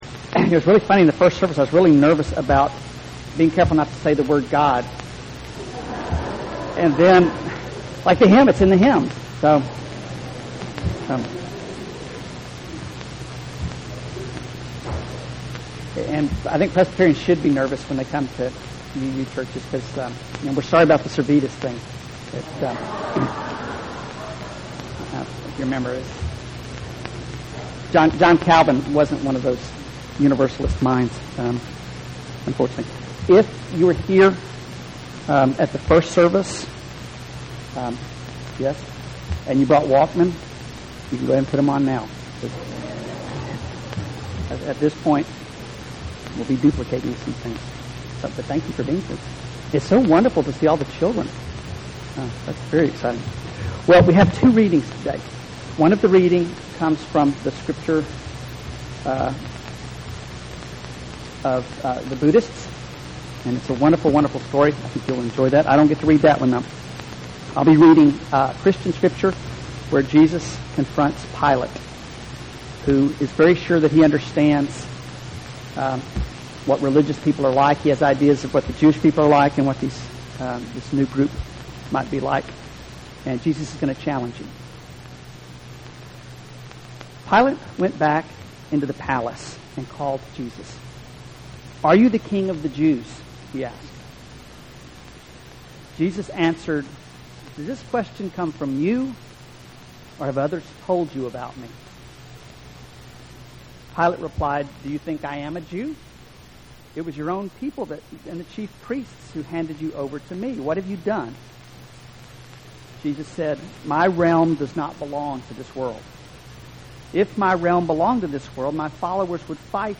2008 The text of this sermon is unavailable but you can listen to the sermon by clicking the play button.